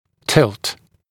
[tɪlt][тилт]наклон, наклонное положение; наклонять; опрокидывать; поворачивать